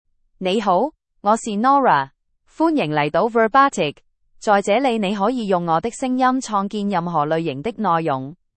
Nora — Female Chinese (Cantonese, Hong Kong) AI Voice | TTS, Voice Cloning & Video | Verbatik AI
Nora is a female AI voice for Chinese (Cantonese, Hong Kong).
Voice sample
Female
Chinese (Cantonese, Hong Kong)
Nora delivers clear pronunciation with authentic Cantonese, Hong Kong Chinese intonation, making your content sound professionally produced.